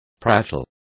Also find out how charle is pronounced correctly.